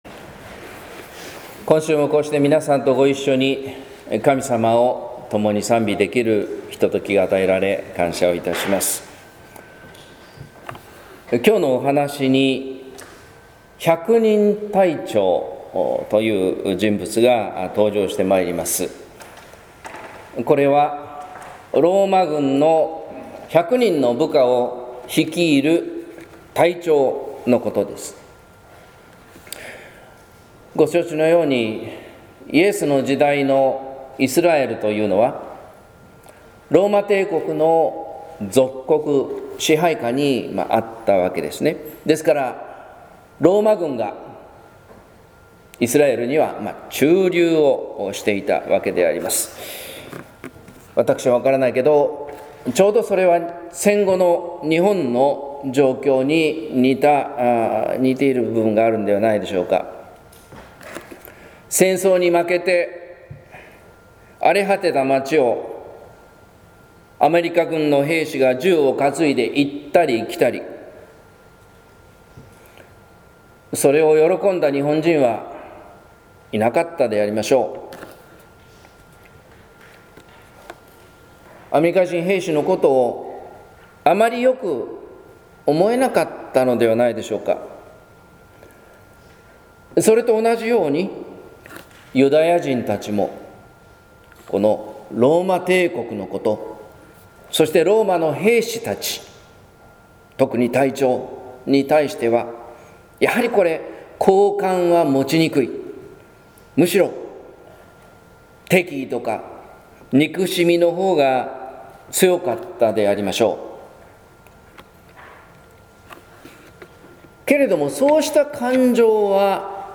説教「愛と信の聖なる関係」（音声版）